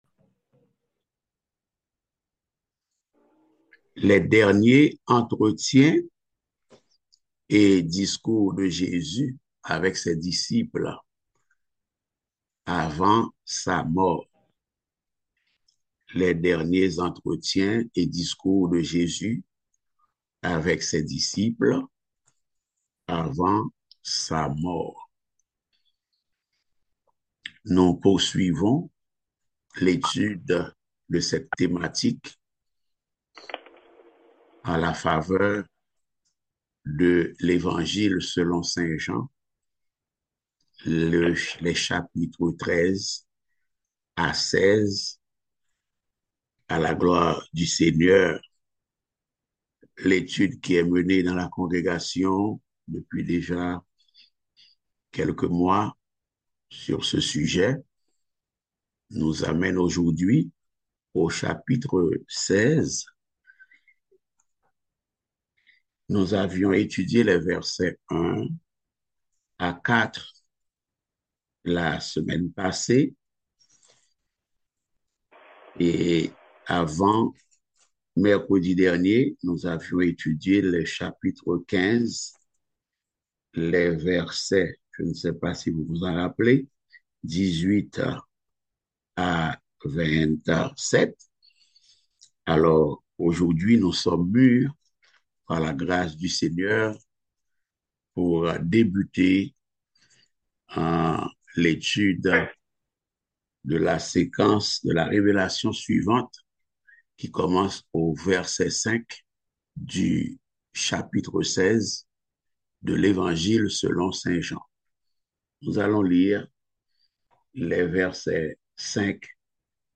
Passage: Jean 16.5-12 Type De Service: Études Bibliques « Quatre principes caractéristiques d’une femme vertueuse